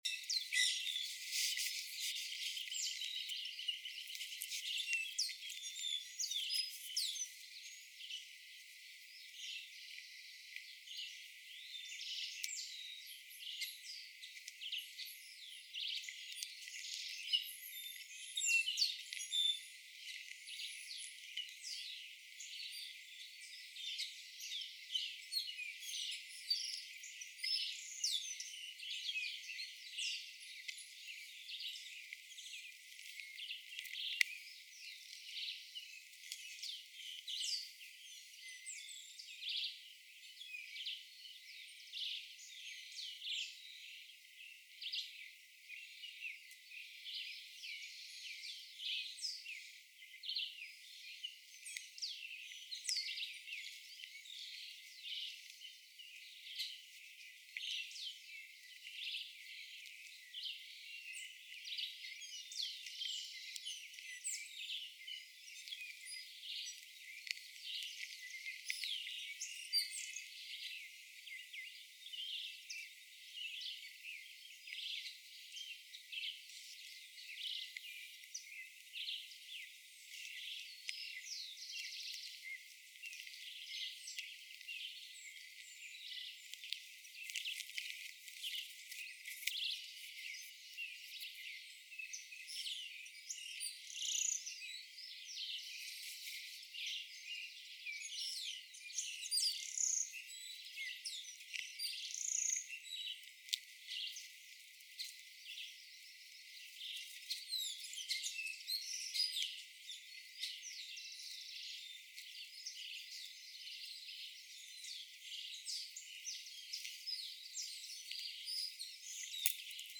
Aquí te proponemos Cinco minutos en la RECS. Son tres archivos de audio, de cinco minutos cada uno, que te permiten escuchar los sonidos de la Reserva: sus aves, la gente que pasa, el ambiente en general.
Los audios fueron grabados el domingo 9 de noviembre de 2014, entre las 7:53 am y las 8:12 am, en el sector de la Laguna de los Coipos).
Filtro +2 Khz: recorrida1_f2k.mp3